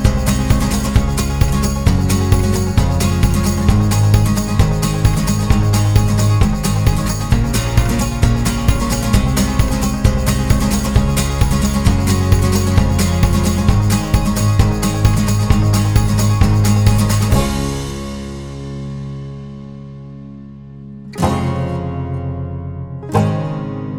no Backing Vocals Easy Listening 4:36 Buy £1.50